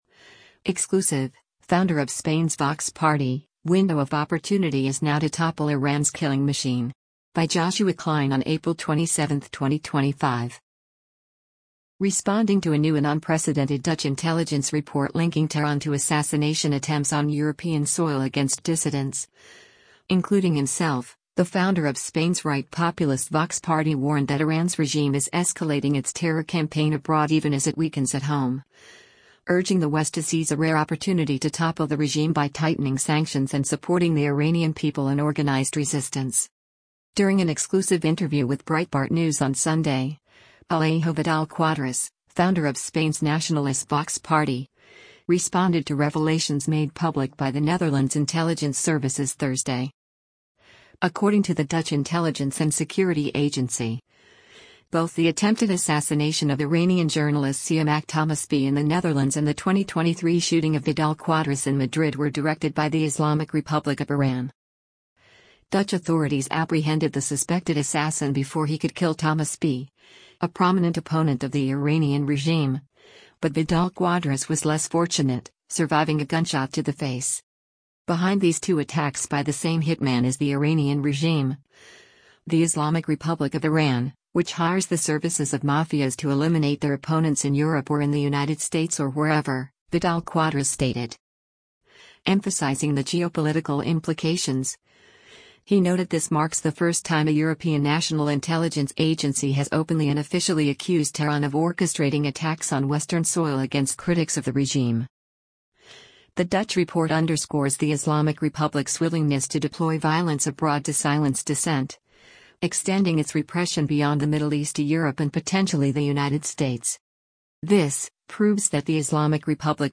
During an exclusive interview with Breitbart News on Sunday, Alejo Vidal-Quadras, founder of Spain’s nationalist Vox Party, responded to revelations made public by the Netherlands’ intelligence services Thursday.